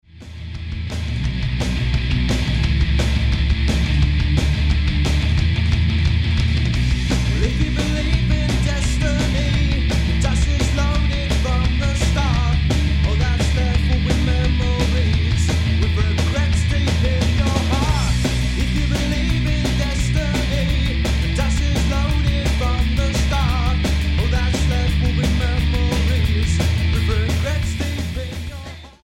Hard Music
London rockers